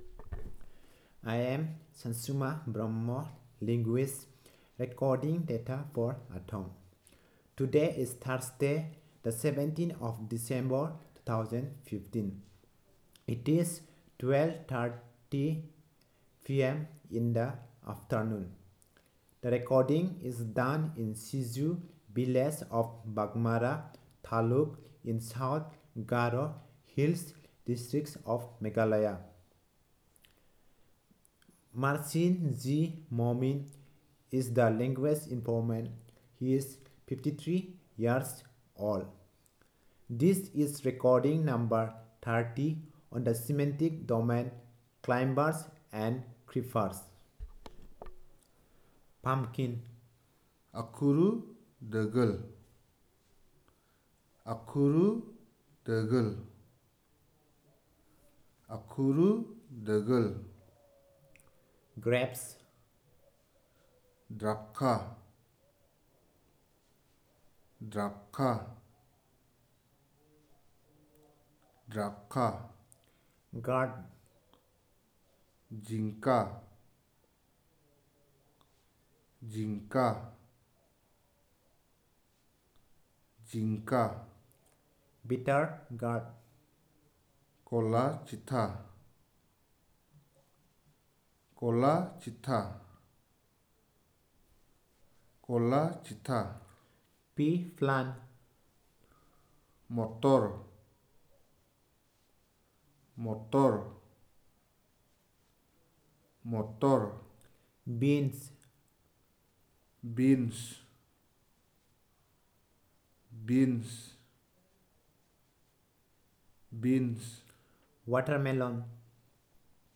Elicitation of words about climbers and creepers
NotesThis is an elicitation of words about climbers and creepers.